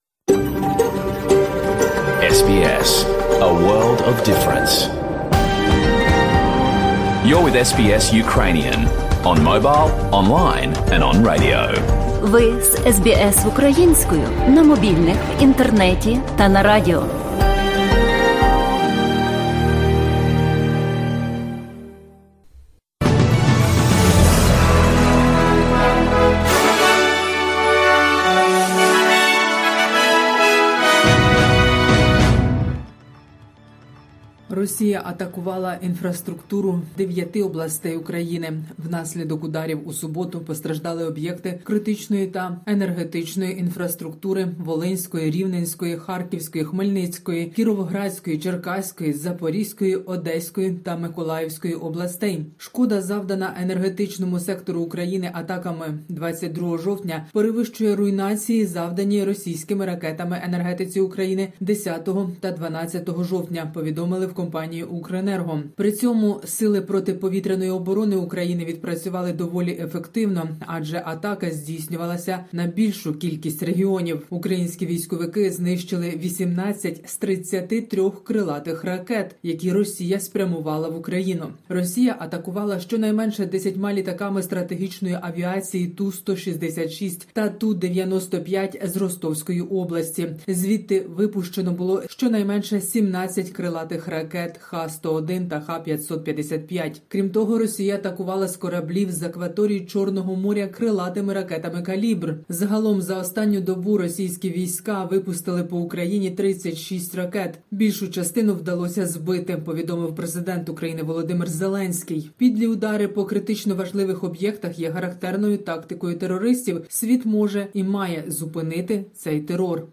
SBS news in Ukrainian - 23/10/2022